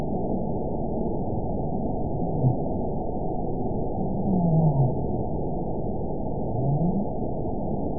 event 922123 date 12/26/24 time 22:48:33 GMT (11 months, 1 week ago) score 9.57 location TSS-AB04 detected by nrw target species NRW annotations +NRW Spectrogram: Frequency (kHz) vs. Time (s) audio not available .wav